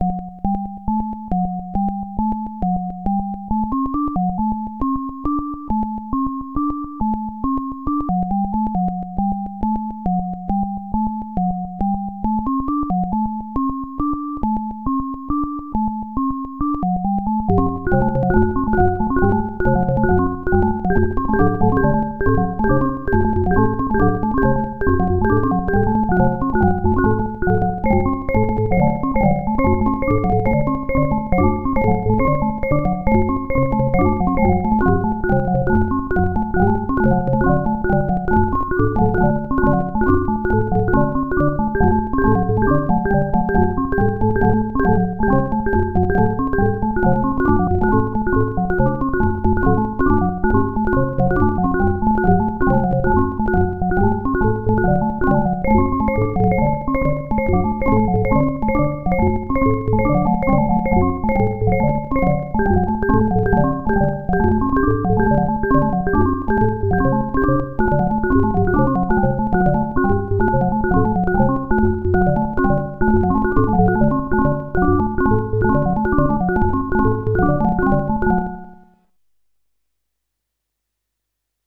It can do microtonal synthesis using 8 oscillators, each having 32 8-bit waveforms to choose from, and 4 very rough amplitude envelope generators.
Pitches can either be defined using ratios or cents for microtonal synthesis and the sequencing algorithms are completely independent of each other for multitemporal sequencing.
knyst_synthesizer_02.mp3